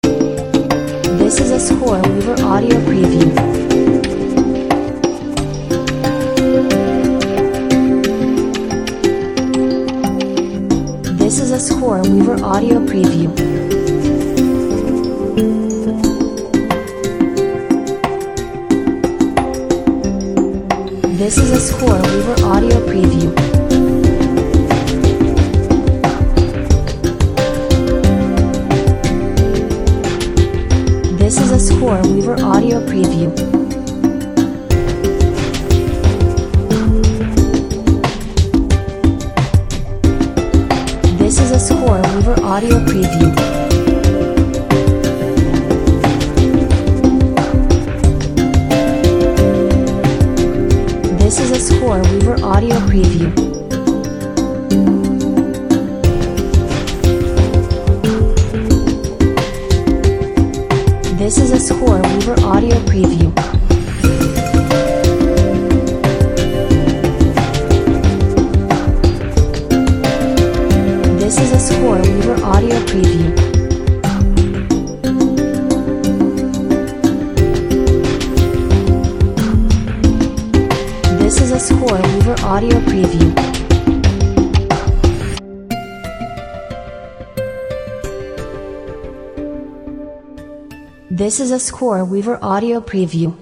Optimistic and mysterious Asian Fusion Track.
Chinese instruments, Congas and a lovely melody…